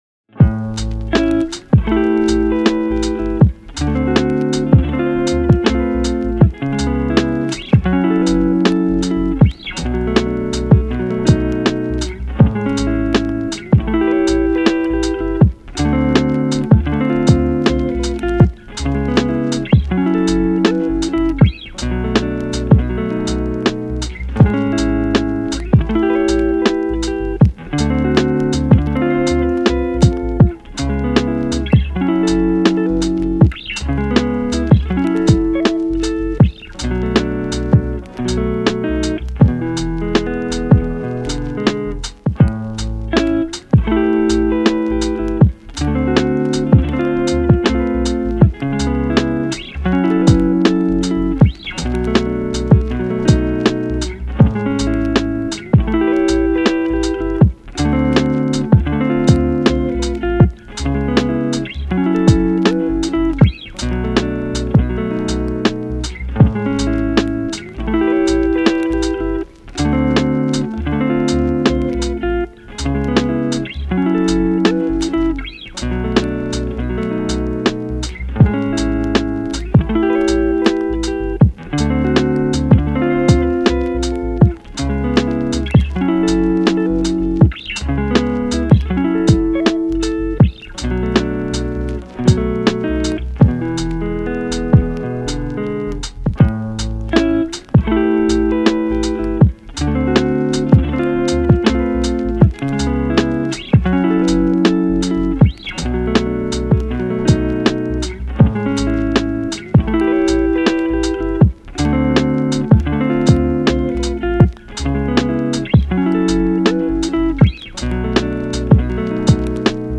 カフェミュージック
チル・穏やか